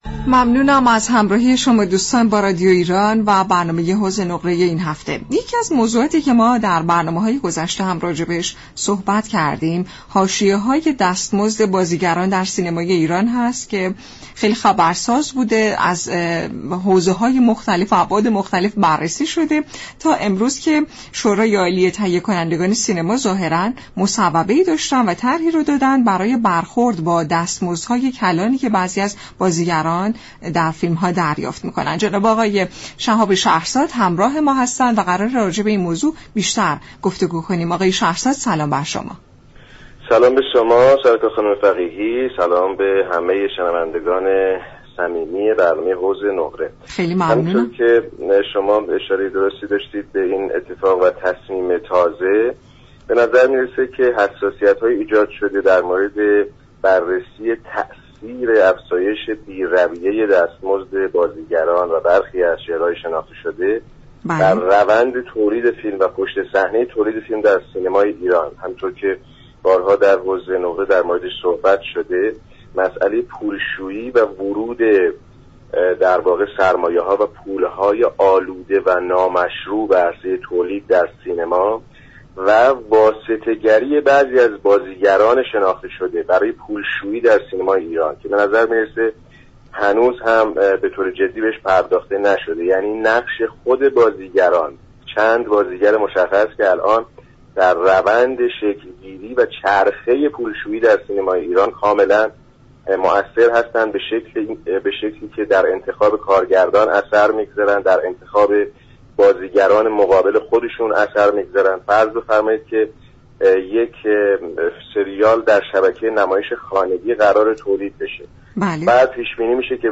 یك تهیه كننده سینما در گفت و گو با برنامه «حوض نقره» به دستمزدهای بالای برخی بازیگران اشاره كرد و گفت: پرداخت دستمزدهای نجومی به برخی بازیگران سینمای ایران از اوائل سال 92 شدت یافت، تا پیش از سال 92 دستمزدها شكلی منطقی داشت.